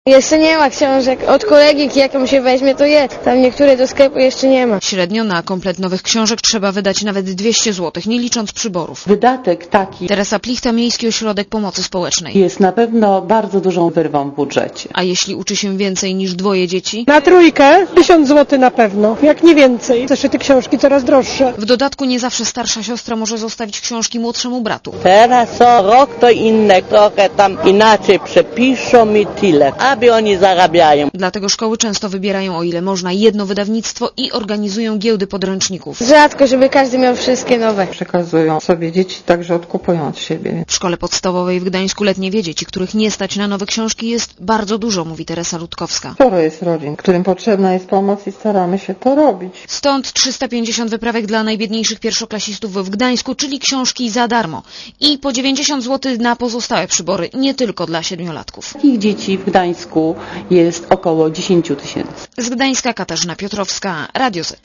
* Posłuchaj relacji reporterskiej z Gdańska*